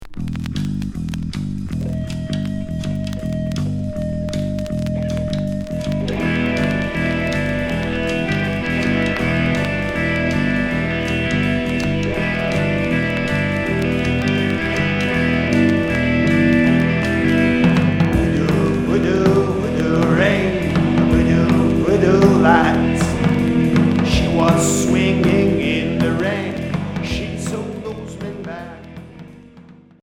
Cold wave